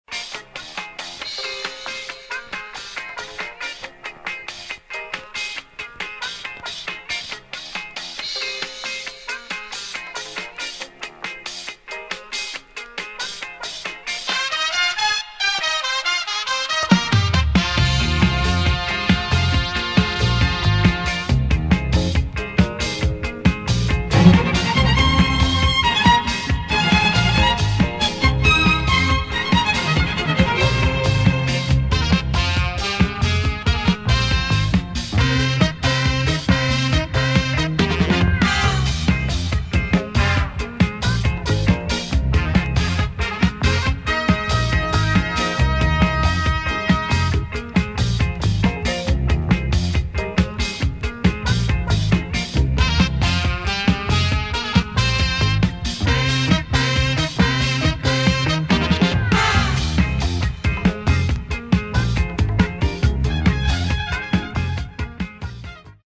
[ DISCO / EDIT / FUNK / SOUL ]